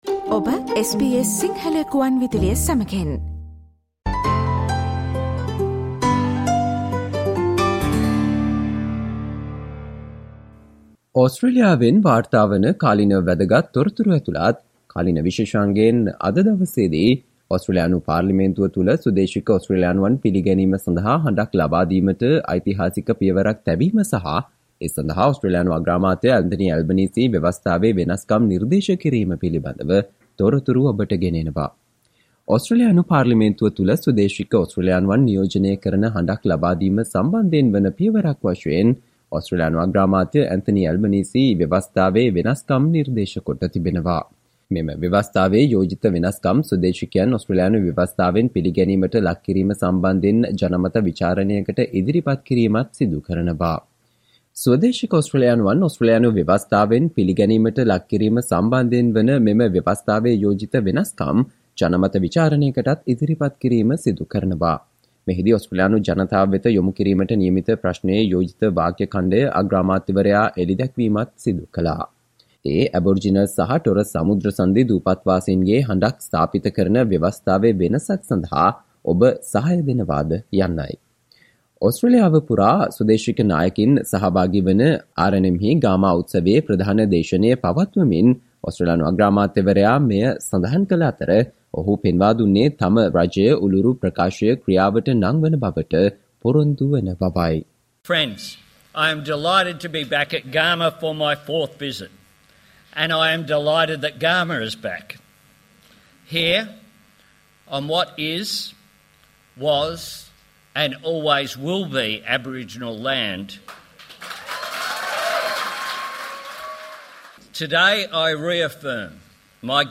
අගෝස්තු 01 වන දා සඳුදා ප්‍රචාරය වූ SBS සිංහල සේවයේ කාලීන තොරතුරු විශේෂාංගයට සවන්දෙන්න.